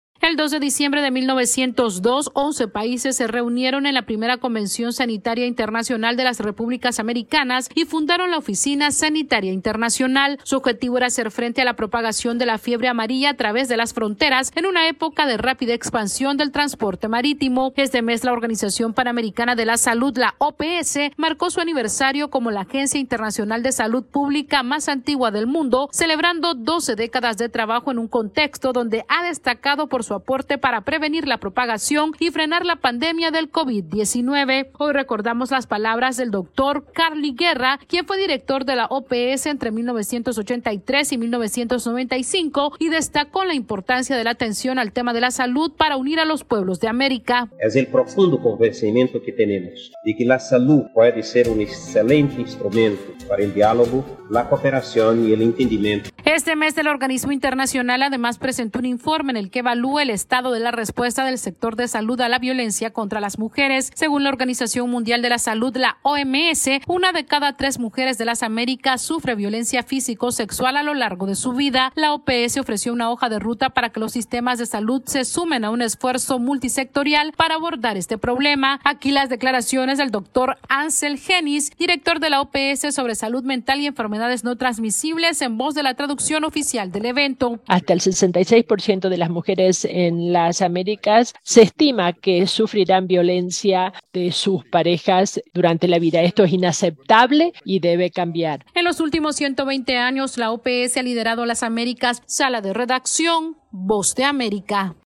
La Organización Panamericana de la Salud celebró 120 años de existencia y pidió que se mejoren los servicios de salud y la violencia contra las mujeres. Esta es una actualización de nuestra Sala de Redacción.